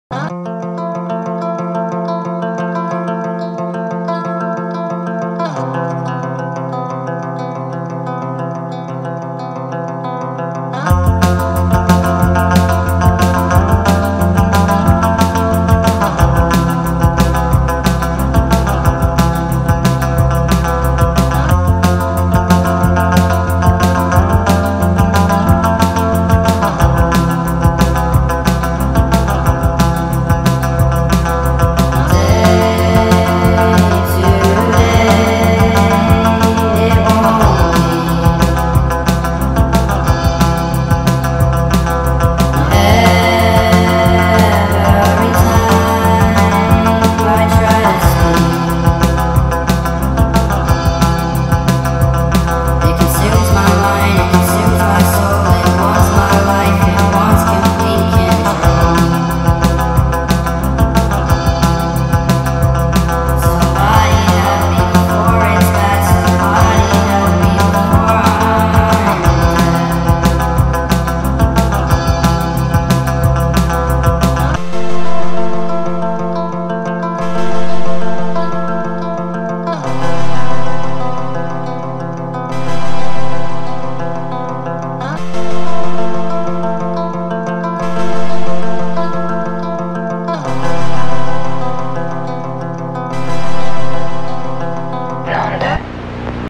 با ریتمی سریع شده
غمگین